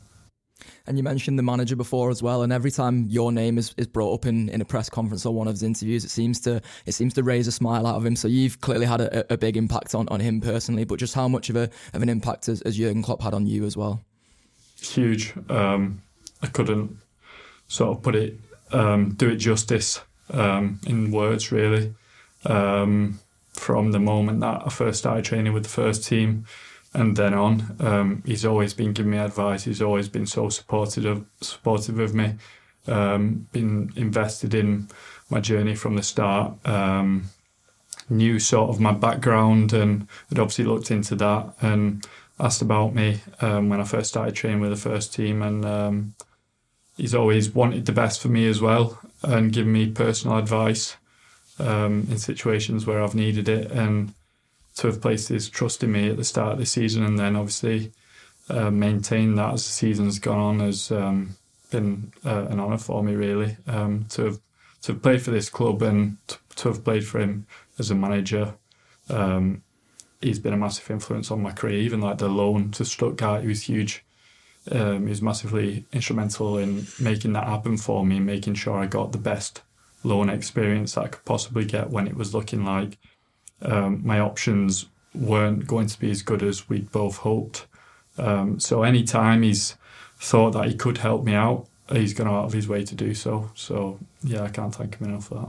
Nat speaks to The Anfield Wrap about his family’s influence, the support from Jurgen Klopp and his teammates, playing during the pandemic, fighting the good fight off the field and what the future holds for him.